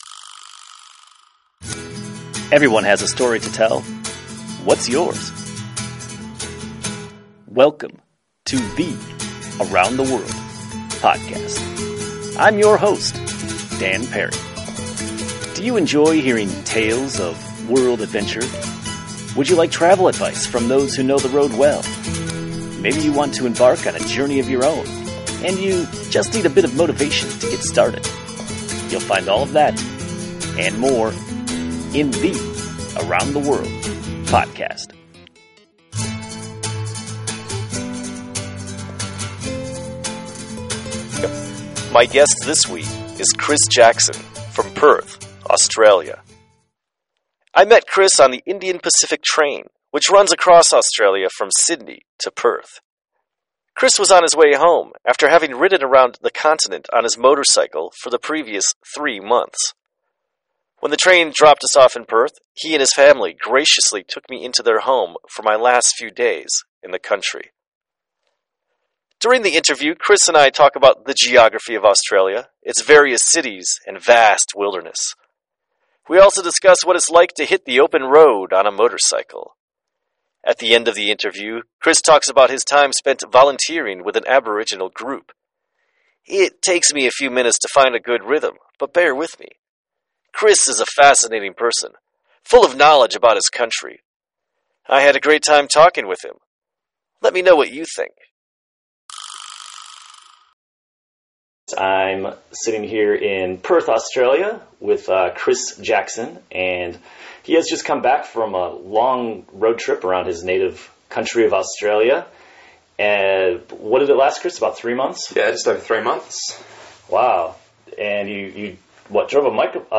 Here are links to some of the places we discussed in the interview: